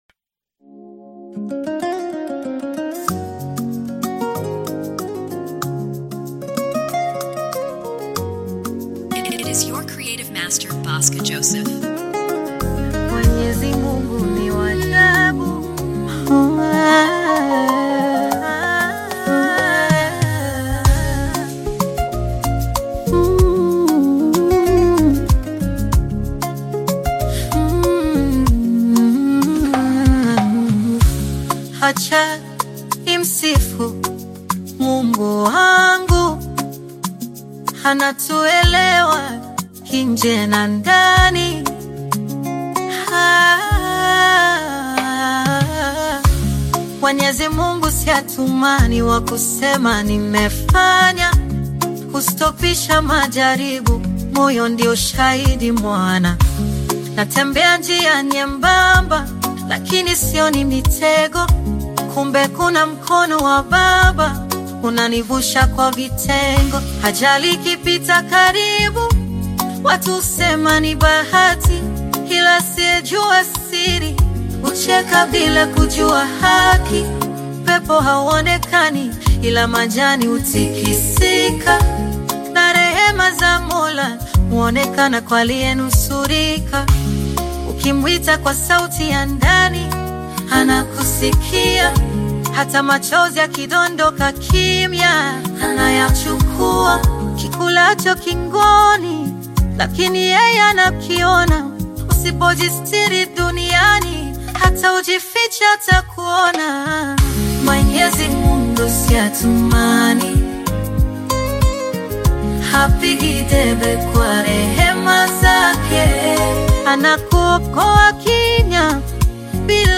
AUDIOKENYAN SONG
guitar